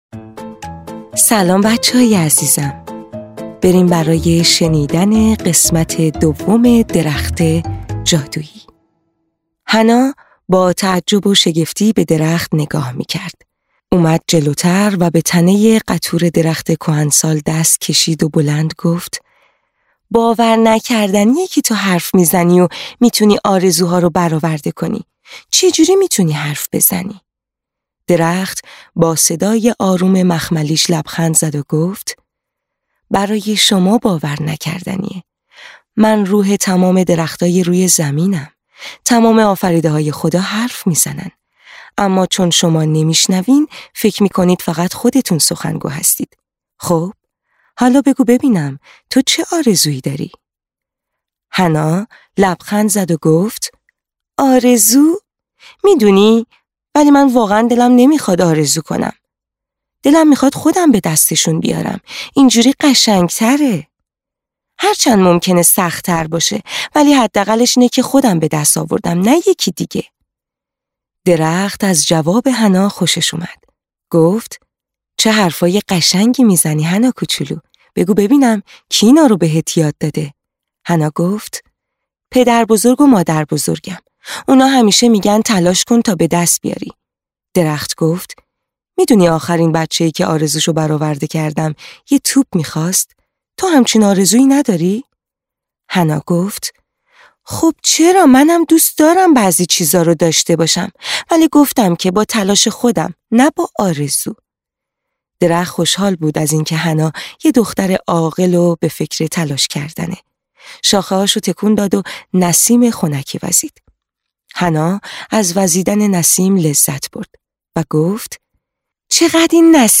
قصه‌های کودکانه صوتی – این داستان: درخت جادویی (قسمت دوم)
تهیه شده در استودیو نت به نت